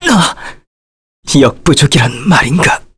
Arch-Vox_Dead_kr.wav